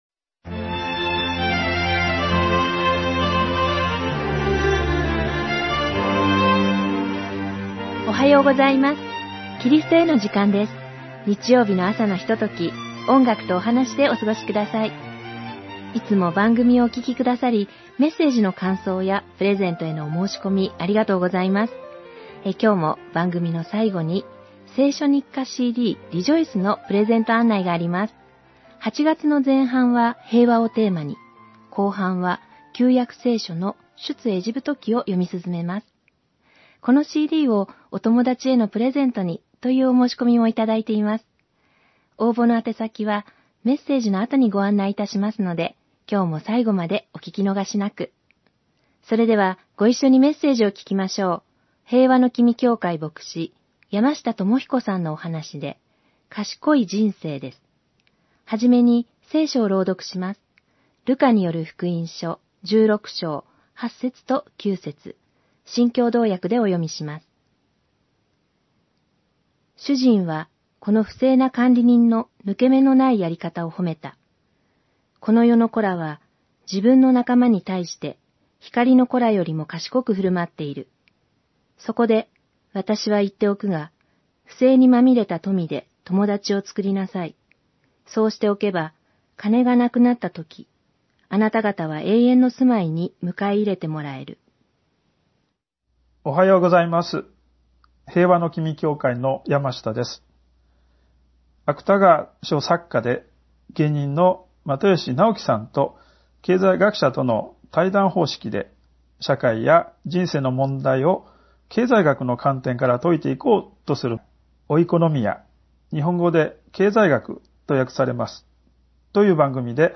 メッセージ： 賢い人生
※ホームページでは音楽著作権の関係上、一部をカットして放送しています。